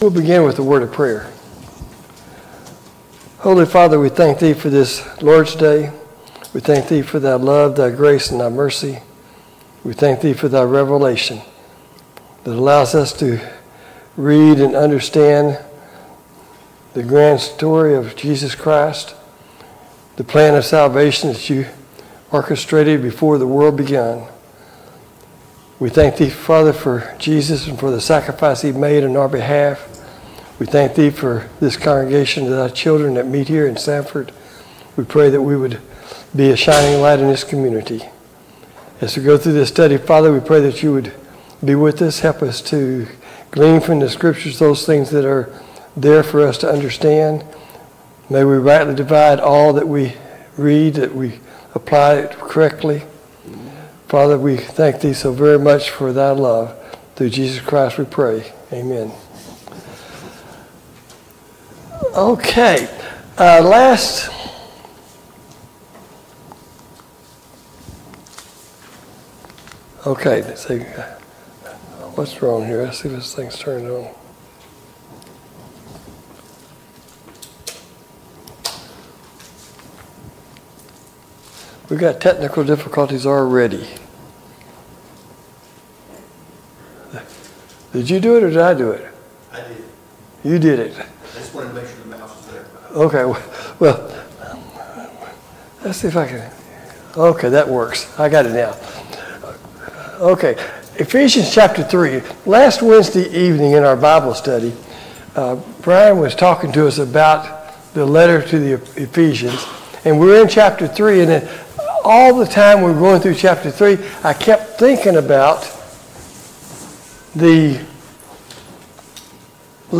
Sunday Morning Bible Class « Study of Paul’s Minor Epistles